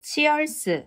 正しい発音は「チアーズ」ですが、韓国では「チアルス」と言っています。